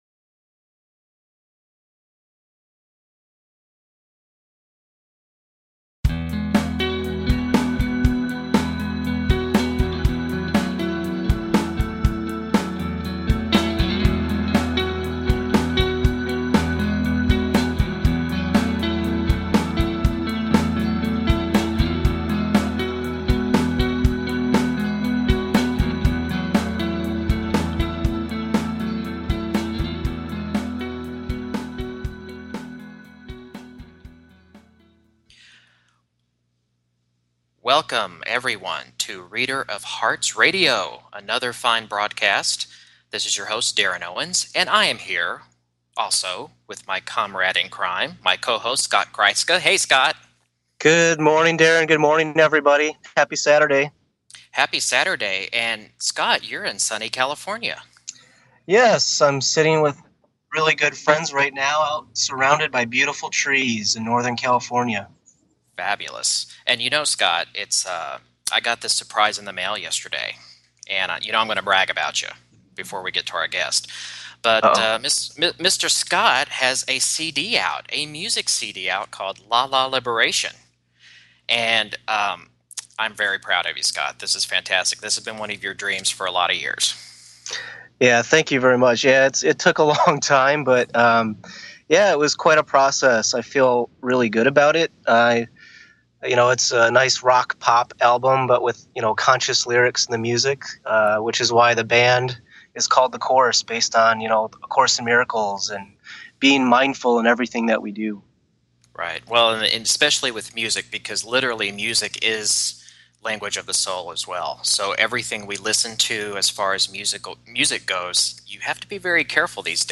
Headlined Show, Reader of Hearts Radio August 2, 2014